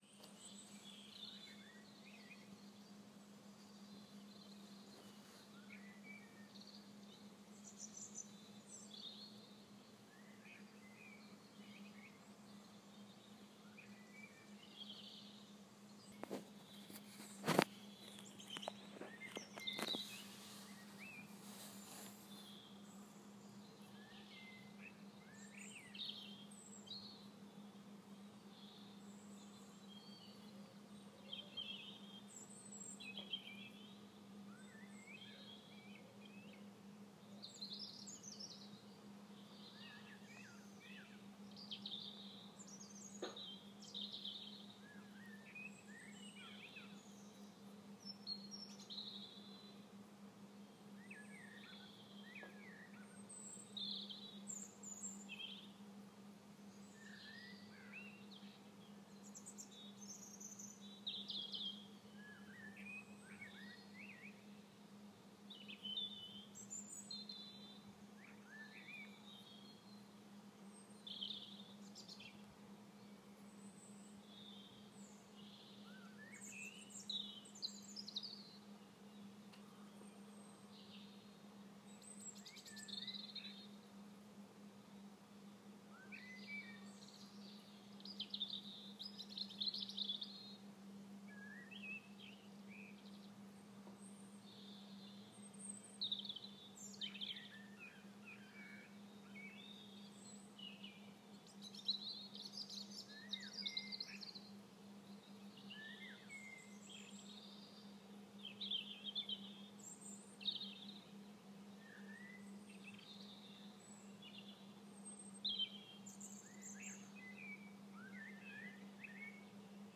However, the other morning, whilst the room was still in darkness, through the solid walls and closed windows I could distinctly hear the sound of birds.
(Please excuse the shuffling sounds at the beginning and towards to end. It’s not always easy holding your phone out of a second storey window)